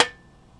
R - Foley 271.wav